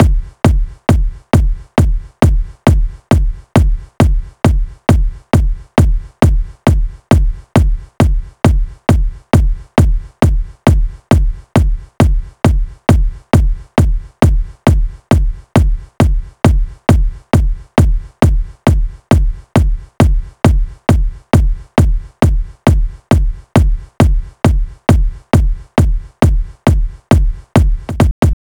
TI CK7 135 Kick.wav